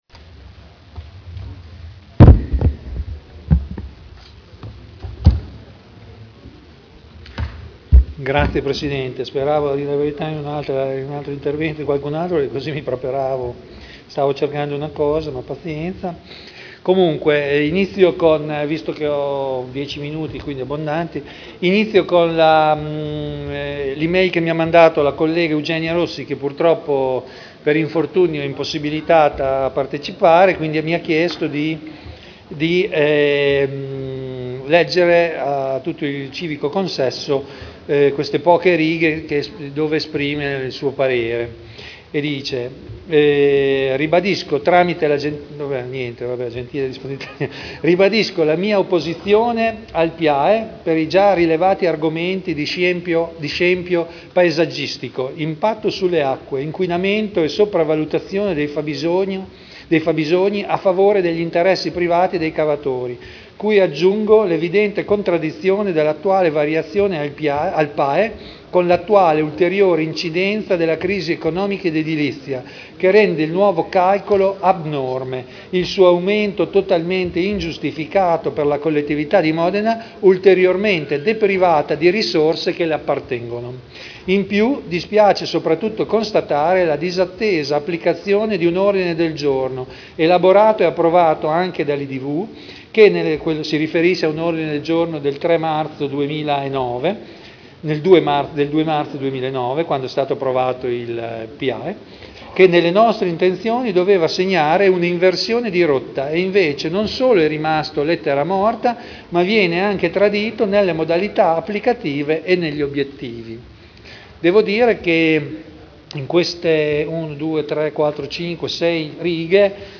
Dibattito. Delibera: Approvazione dell’atto di indirizzo per l’attuazione del Piano delle Attività Estrattive del Comune di Modena (Commissione consiliare del 28 giugno 2011)